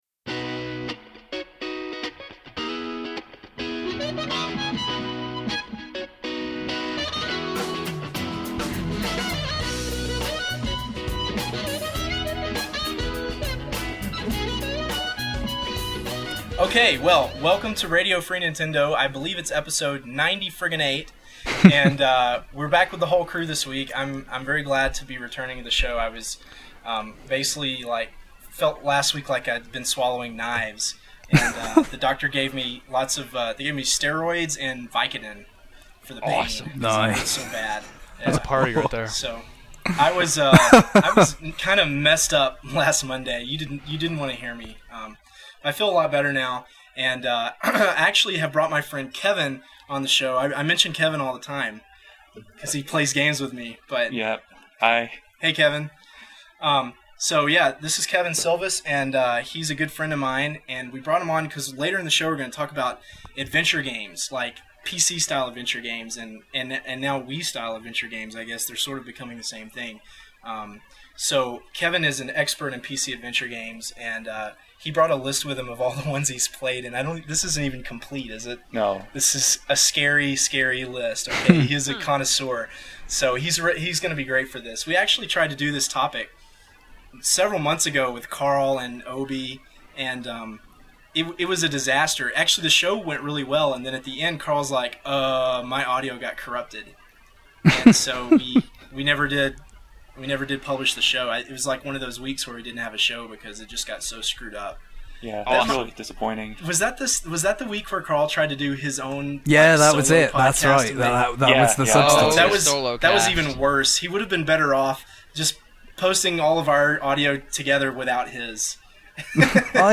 Games discussed included: Speed Racer, Mario Kart Wii, and the legendary return of LifeSigns! After the break the group discusses PC Adventure games.